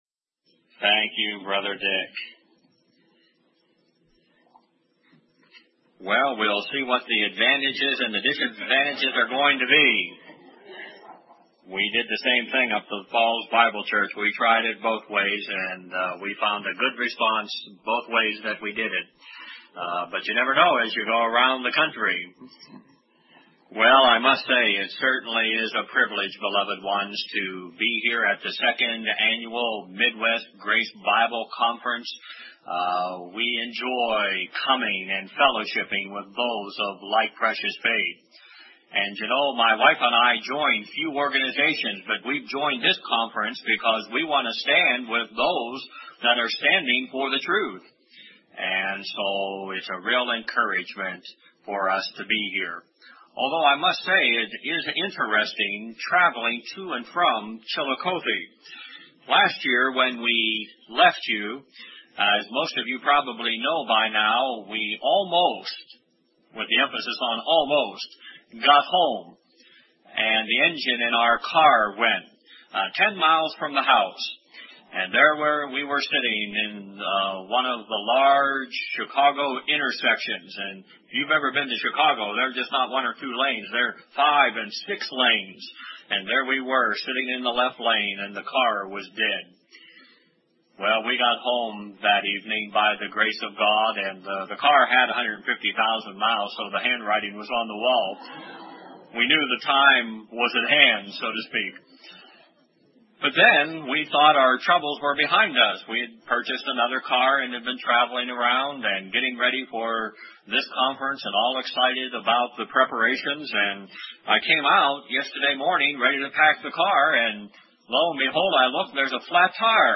A message from the 1990 Spring Bible Conference of the Midwest Grace Fellowship.